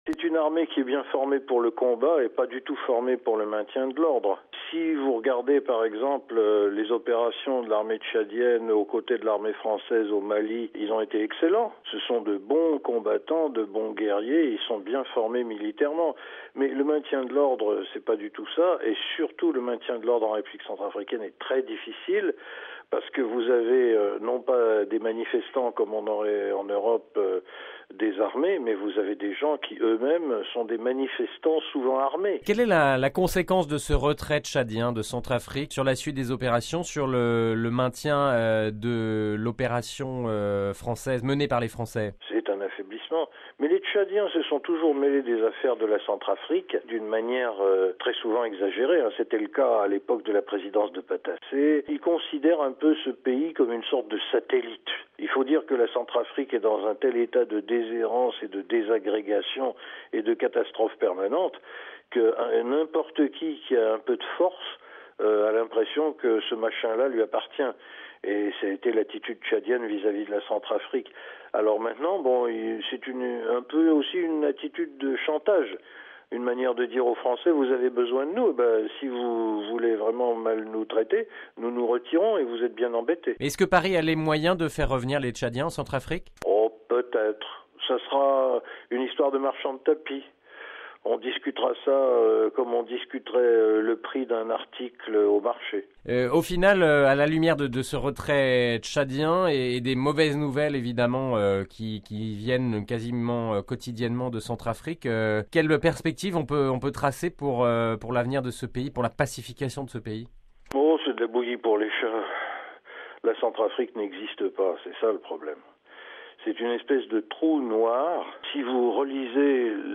(RV) - Entretien. Le Tchad ne compte pas revenir sur sa décision de retirer ses soldats de Centrafrique.